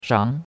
shang2.mp3